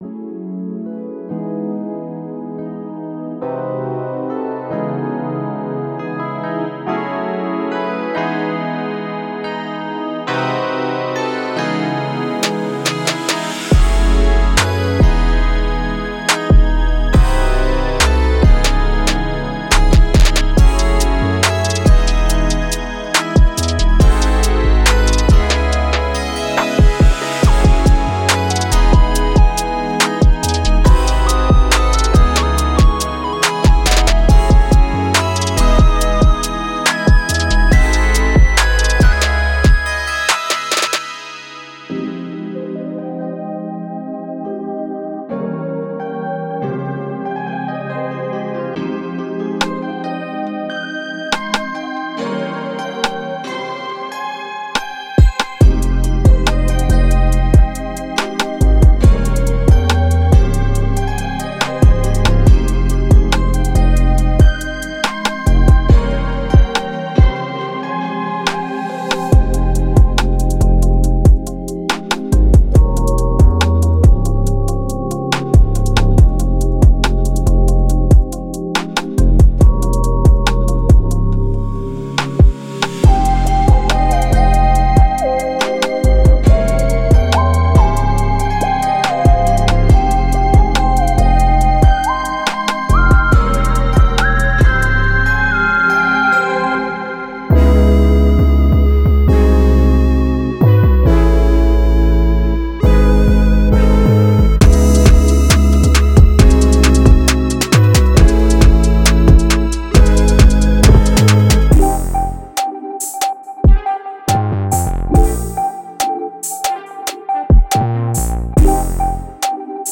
Genre:Trap
ノスタルジックなコード進行やソウルフルなキーから、雰囲気のあるシンセリード、エモーショナルなパッドまで
デモサウンドはコチラ↓